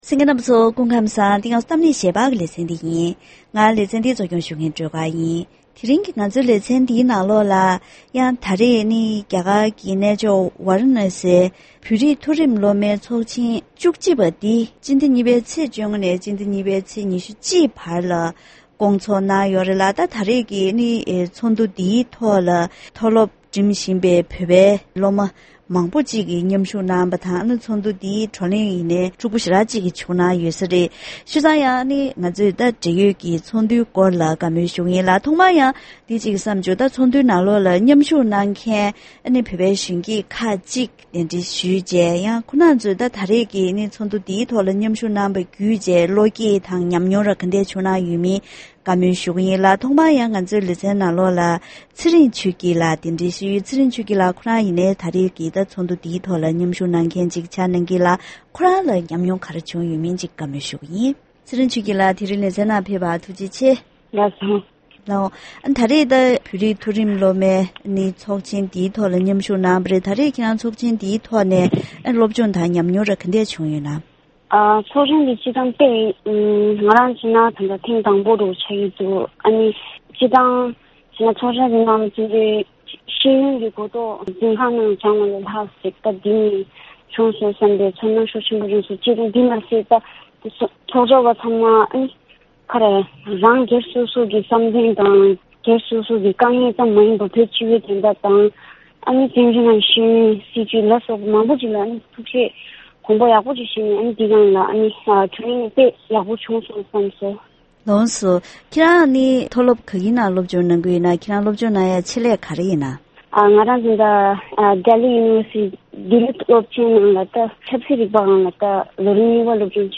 ༄༅། །དེ་རིང་གི་གཏམ་གླེང་ཞལ་པར་ལེ་ཚན་ནང་རྒྱ་གར་གྱི་གནས་མཆོག་ཝཱ་རཱ་ཎ་སཱིར་རྟེན་གཞི་བྱེད་པའི་དབུས་བོད་ཀྱི་གཙུག་ལག་སློབ་གཉེར་ཁང་དུ་བོད་རིགས་མཐོ་རིམ་སློབ་མའི་ཚོགས་ཆེན་ཐེངས་བཅུ་གཅིག་པ་དེ་སྐོང་ཚོགས་གནང་ཡོད་པ་ལྟར། ཚོགས་ཆེན་དེའི་ཐོག་མཉམ་ཞུགས་གནང་མཁན་བོད་པའི་གཞོན་སྐྱེས་ཁག་གཅིག་ལྷན་གཞོན་སྐྱེས་ཀྱི་ལས་འགན་དང་ཚོགས་འདུའི་དམིགས་ཡུལ་སོགས་ཀྱི་གནད་དོན་ཁག་གི་ཐོག་བཀའ་མོལ་ཞུས་པ་ཞིག་གསན་རོགས་གནང་།